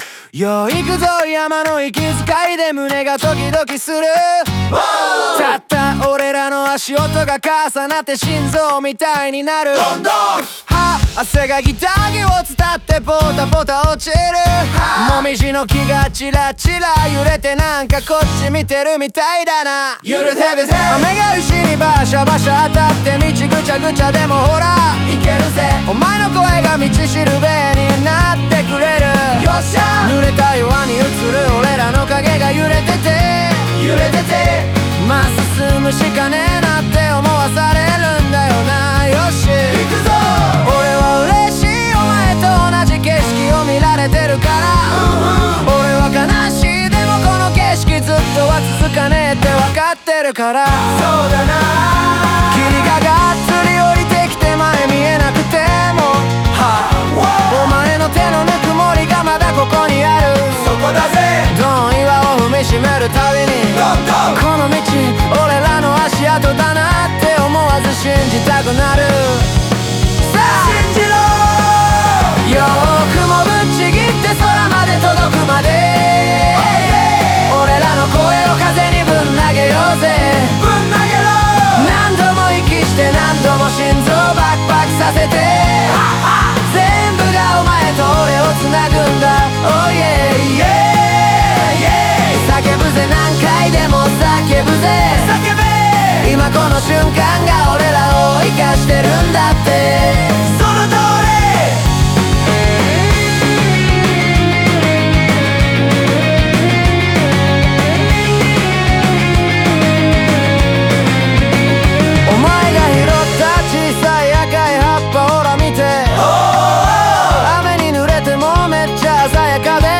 サビでは雲を突き抜ける解放感や愛情を全力で叫ぶ構造で、バックコーラスの掛け声がライブ感と一体感を演出。